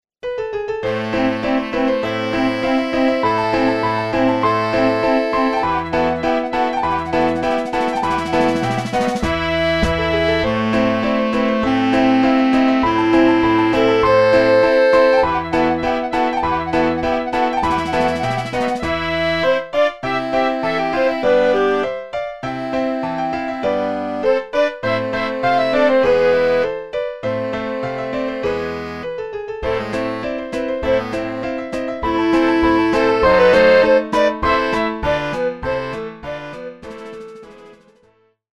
Fortepian solo z towarzyszeniem orkiestry dętej.